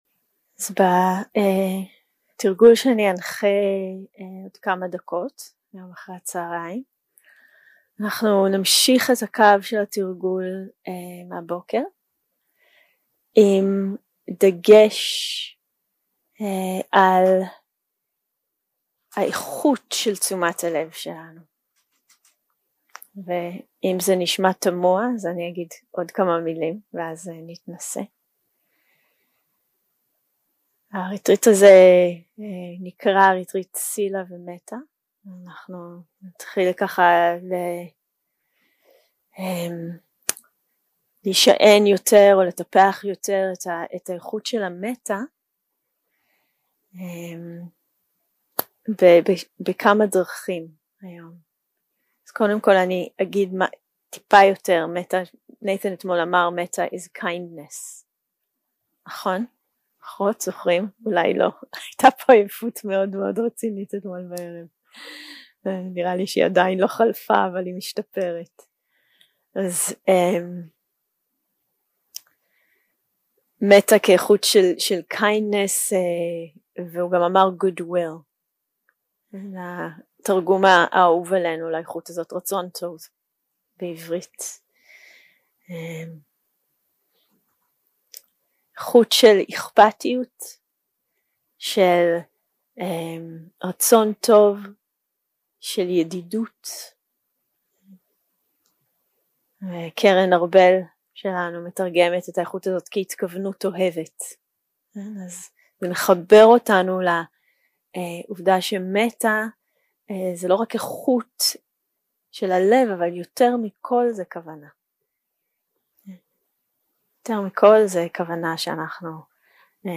יום 2 – הקלטה 3 – צהריים – מדיטציה מונחית – איכות של מטא במפגש 0:00 0:00 סוג ההקלטה: Dharma type: Guided meditation שפת ההקלטה: Dharma talk language: Hebrew